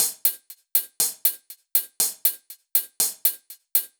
Index of /musicradar/french-house-chillout-samples/120bpm/Beats
FHC_BeatB_120-01_Hats.wav